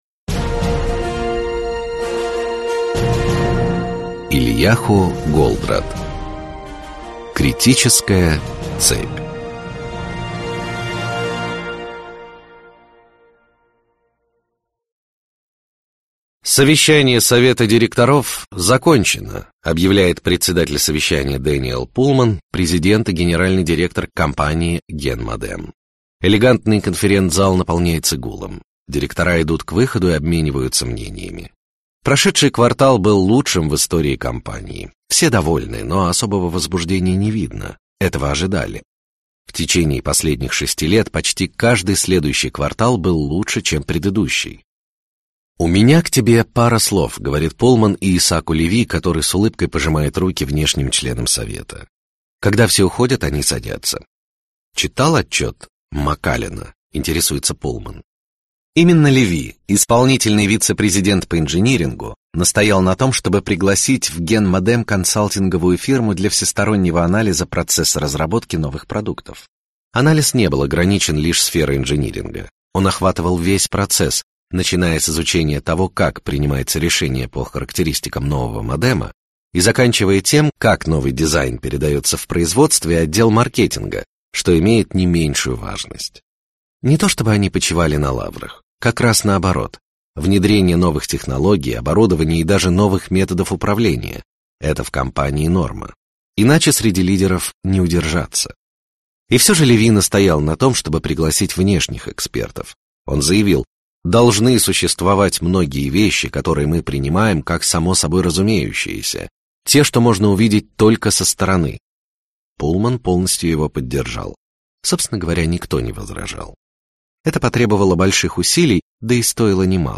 Аудиокнига Критическая цепь | Библиотека аудиокниг